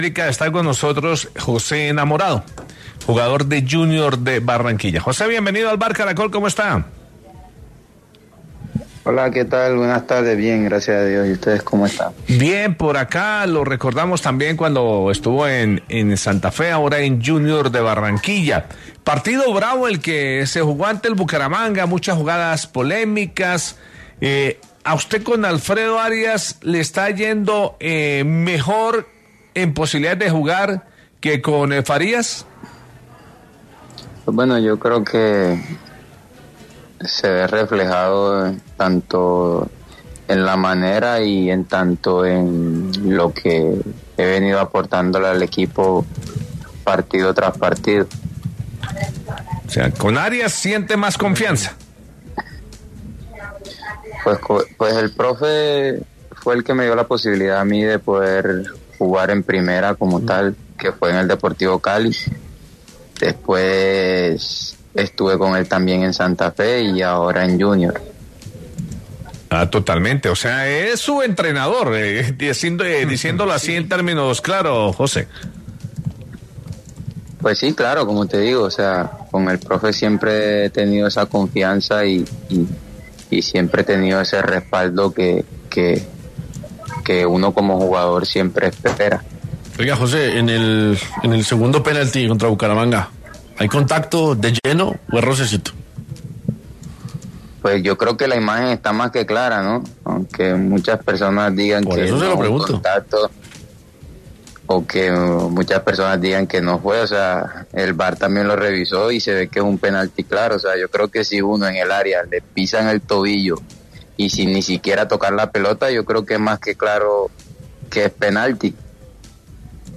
El jugador de Junior de Barranquilla habló con el Vbar de Caracol Radio.